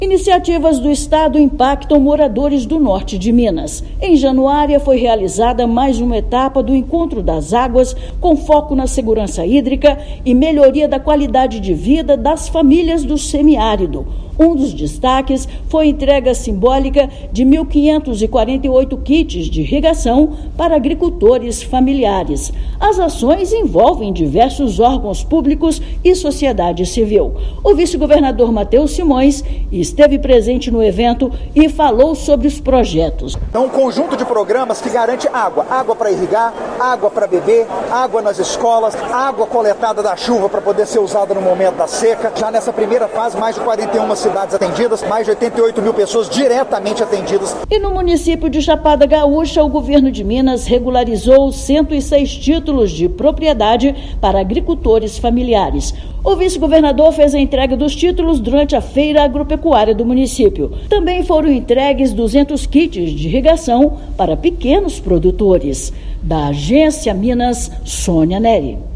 [RÁDIO] Governo de Minas avança em ações de segurança hídrica e de regularização fundiária no Norte do Estado
Agricultores familiares recebem título de propriedade em Chapada Gaúcha e Encontro das Águas realiza mais uma etapa, em Januária. Ouça matéria de rádio.